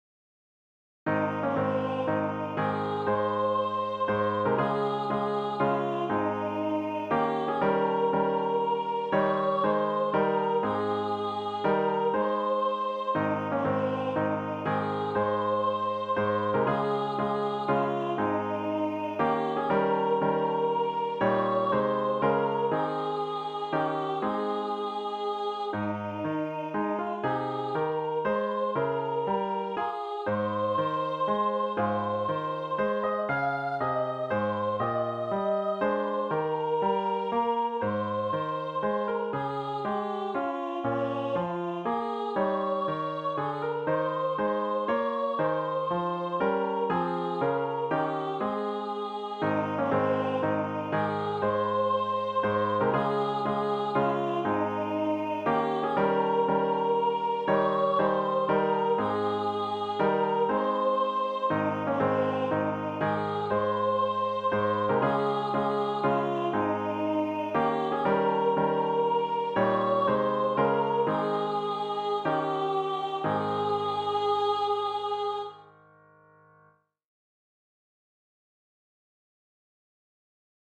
The other songs are presented only with the melody.
In general, the tunes resemble traditional hymns.